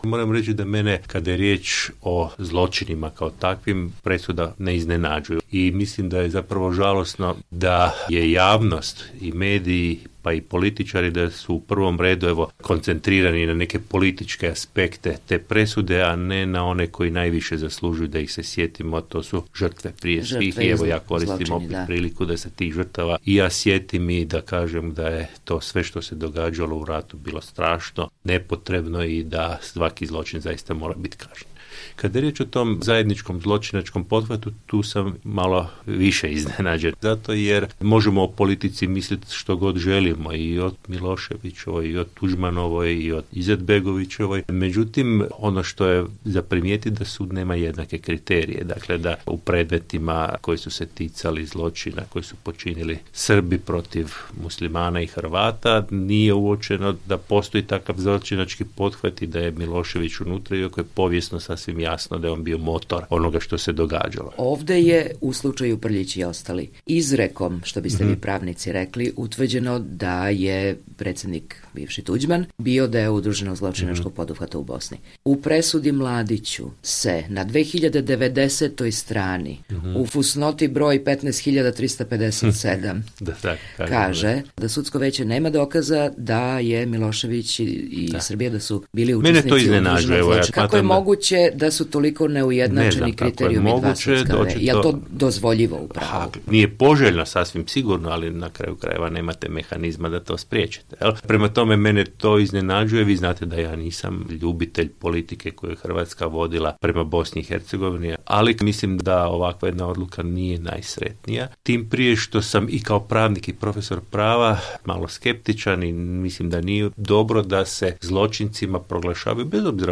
Intervju: Ivo Josipović